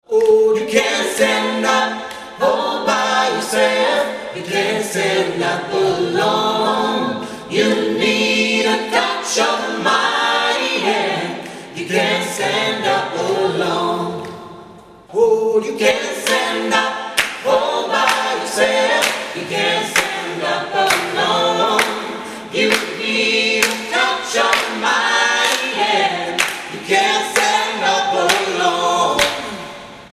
registrazione dal vivo
coro gospel
durante il Matrimonio celebrato nella Chiesa